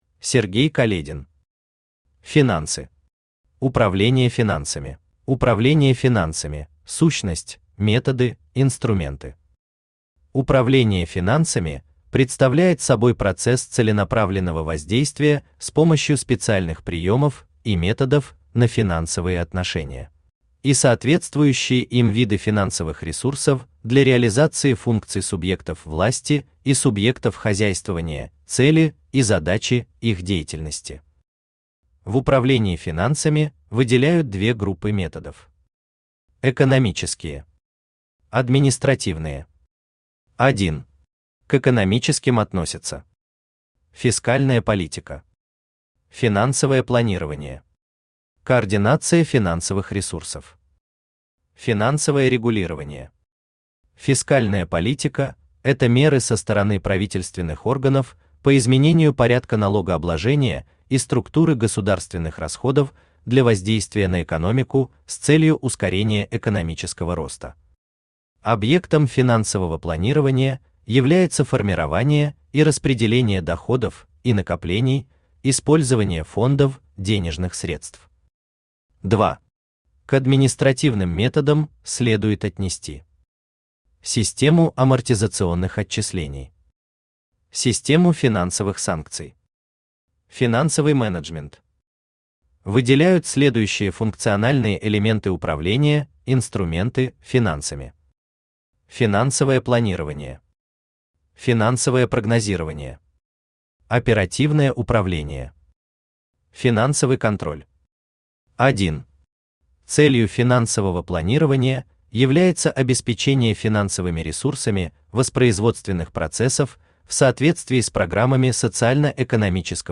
Аудиокнига Финансы. Управление финансами | Библиотека аудиокниг
Управление финансами Автор Сергей Каледин Читает аудиокнигу Авточтец ЛитРес.